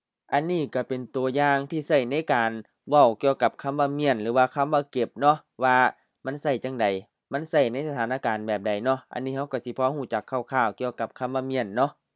Vocabulary recordings — เมี้ยน 7